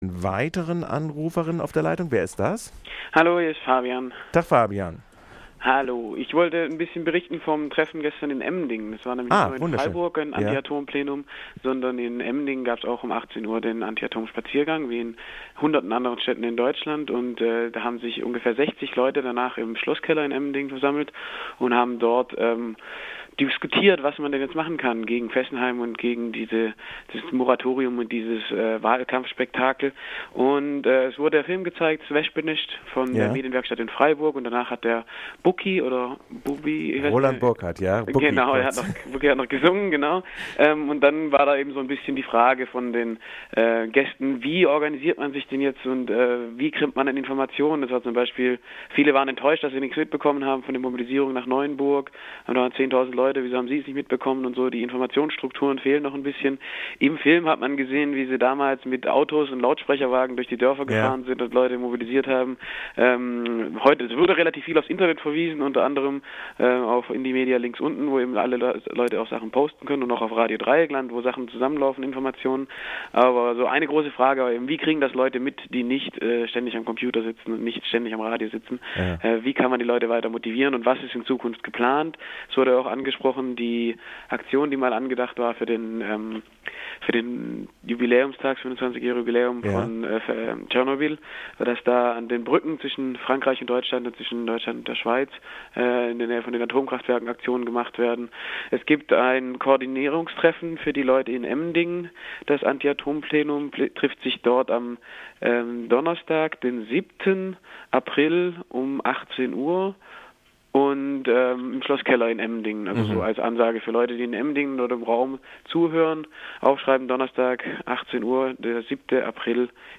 Anruf zu Aktion in Emmendingen